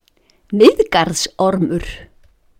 Listen to pronunciation: Miðgarðsormur Please note that the accent is always on the first syllable in Icelandic